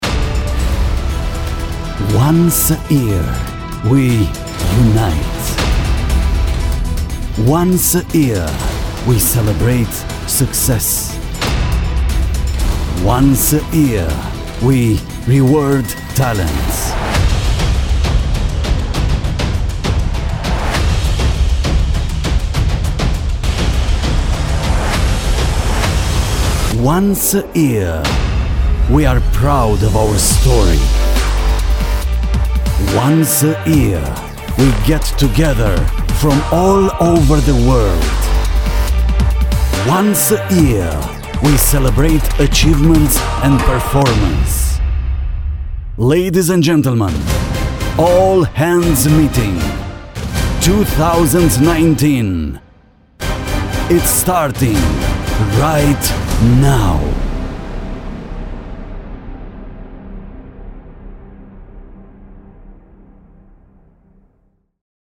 Sprechprobe: Industrie (Muttersprache):
I have a warm, sexy voice and if I am asked I can read and interpret aggressive passages.